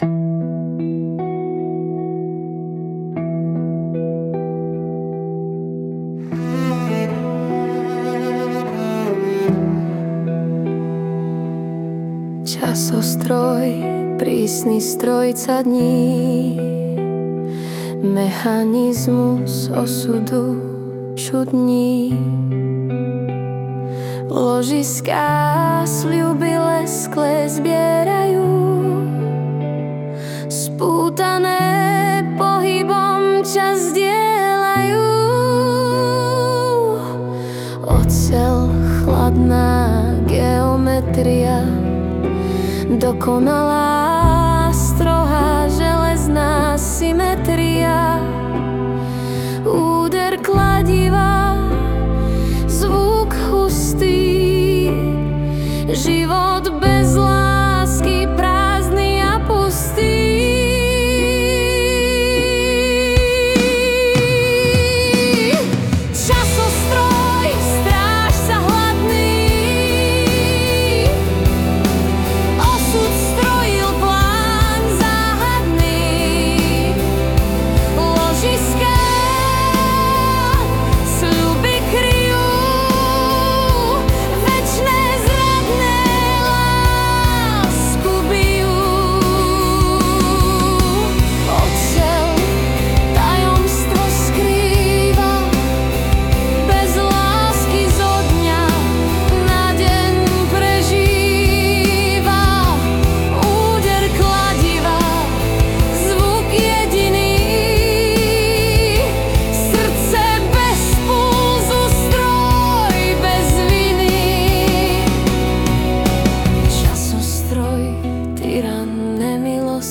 Hudba a spev AI
Balady, romance » Ostatní